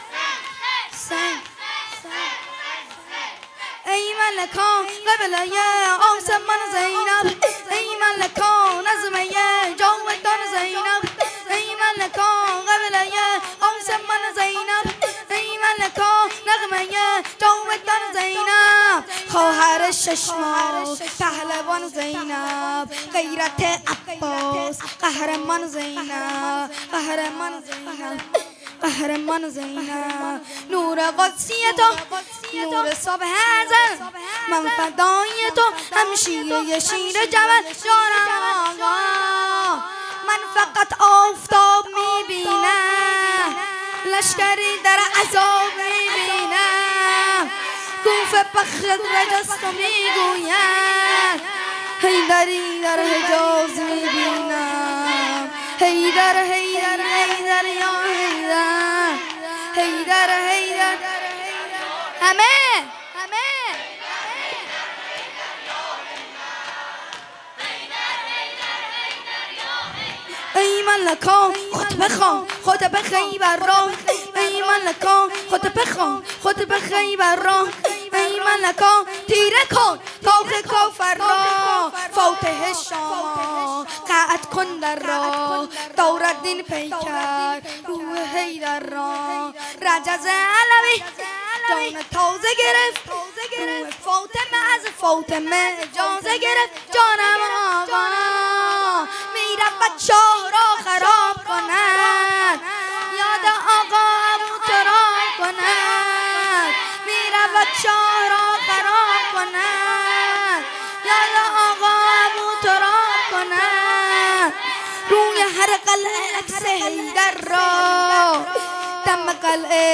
شور| ای ملکا قبله ی آسمان زینب بامداحی
هیئت‌ هفتگی انصار سلاله النبی